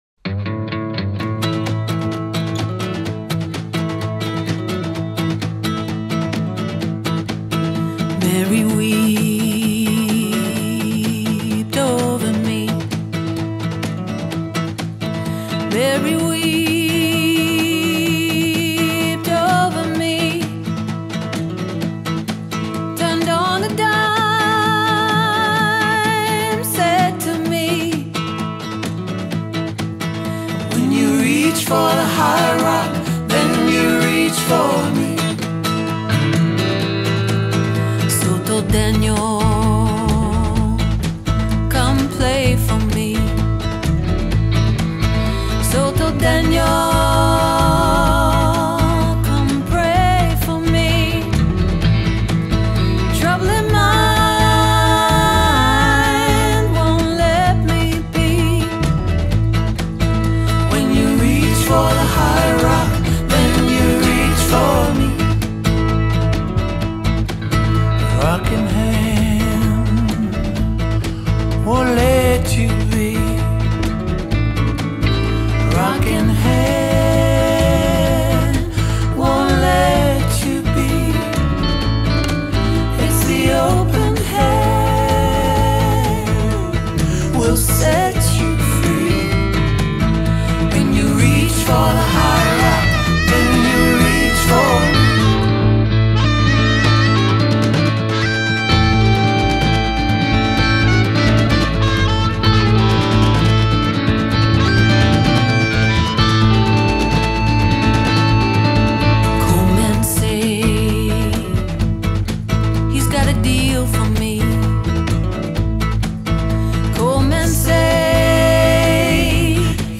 Instead, it leans into texture, mood, and forward motion.